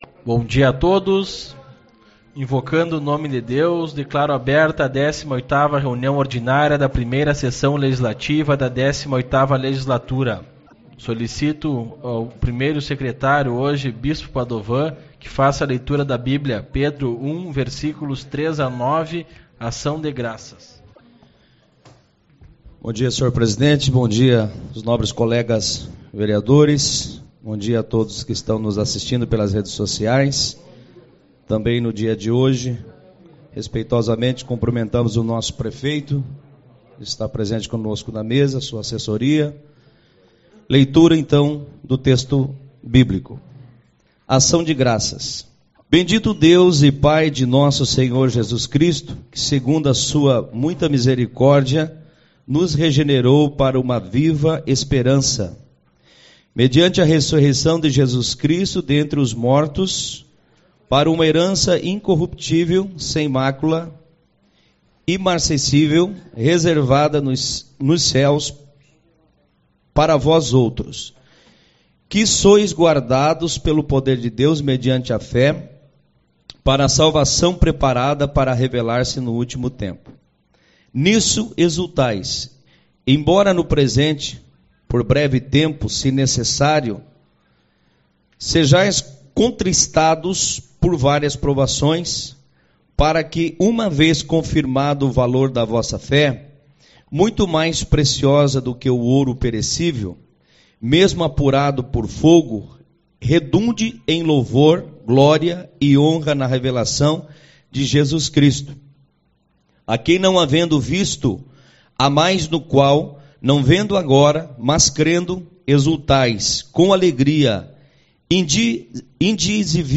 01/04 - Reunião Ordinária